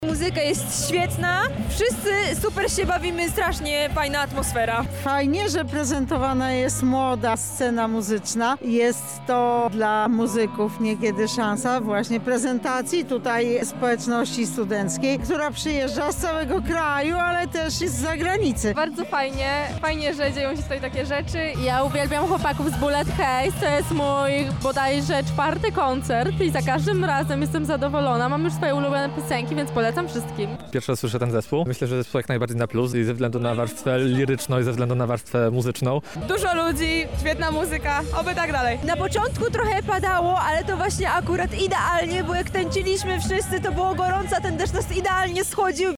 O swoich wrażeniach opowiedzieli nam uczestnicy koncertu:
SONDA